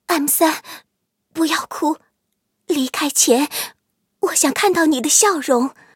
M5斯图亚特被击毁语音.OGG